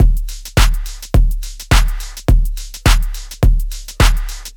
• hard pop big kick loop.wav
hard_pop_big_kick_loop_cEN.wav